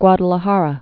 (gwŏdl-ə-härə, gwädä-lä-härä)